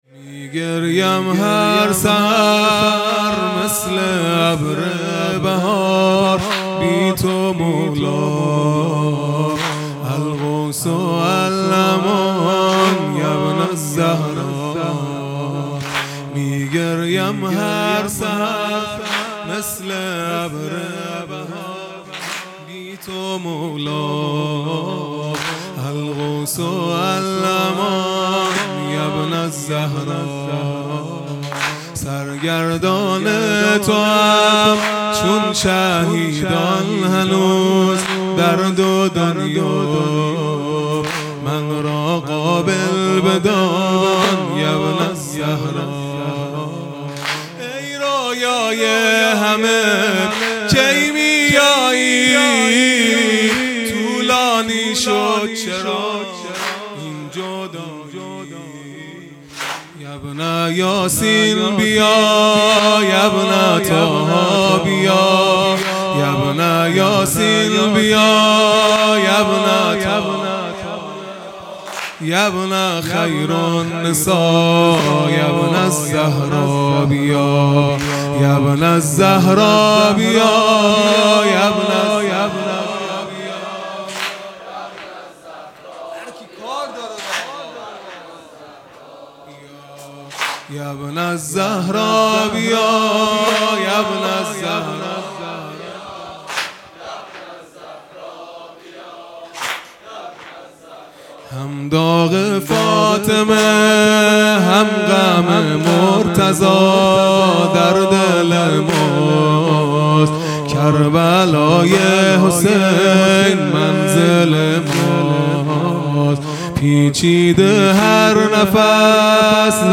شب سوم قدر